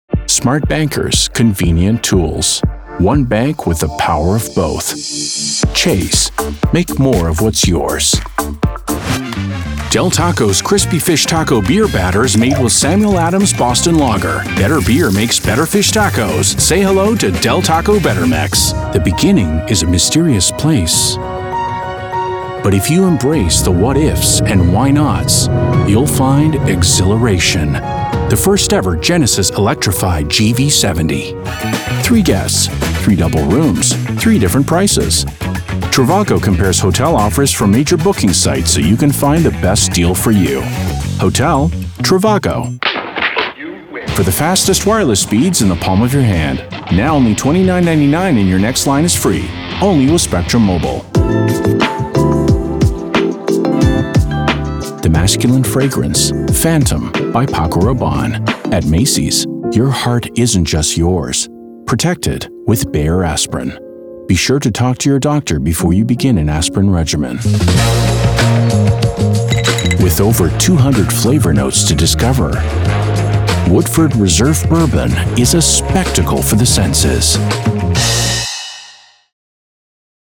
Commercial Demo
Middle Aged
My broadcast quality studio includes an acoustically treated iso-booth and industry standard equipment including:
-Sennheiser MKH-416 mic